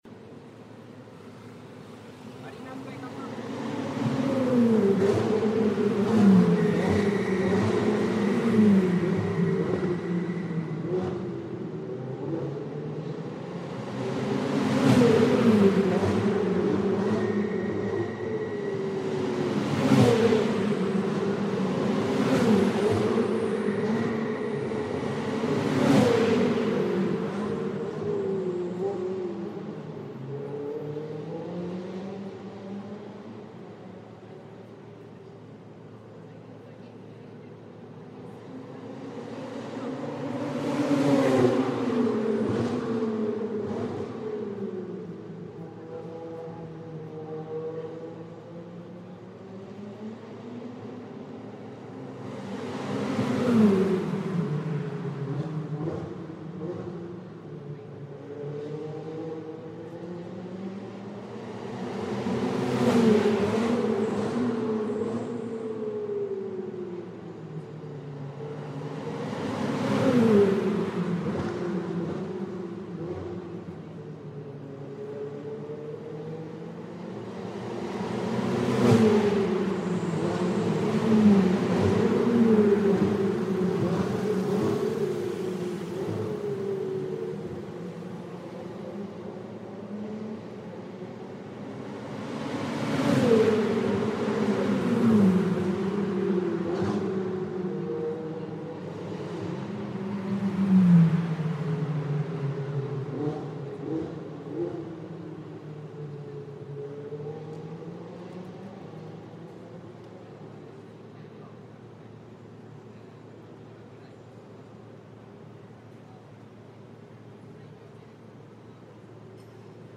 Racing car trials taking place around the streets of Singapore past the Hotel Marina Mandarin - high-speed car flypasts are the order of the day.